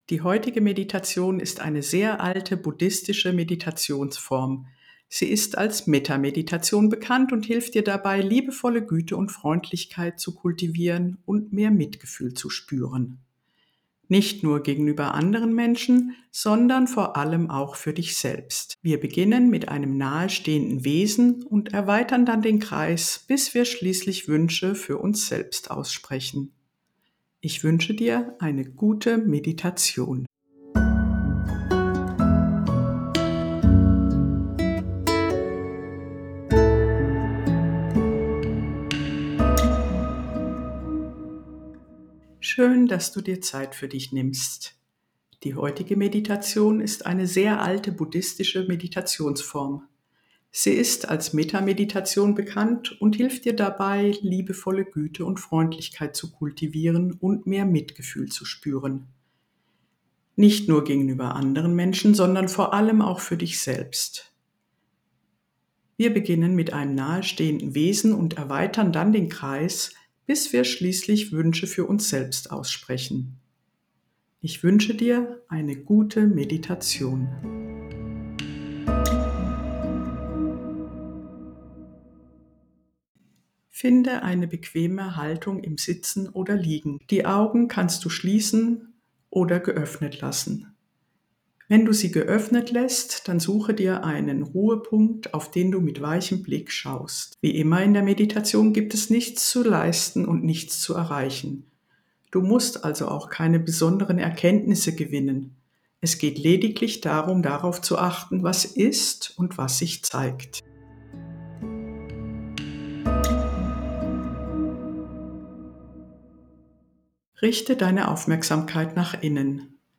Im heutigen Impuls gibt es eine geführte Metta-Meditation.
Der heutige Impuls ist eine sanfte, 10-minütige Metta-Meditation.
Metta_Meditation_MitMusik.mp3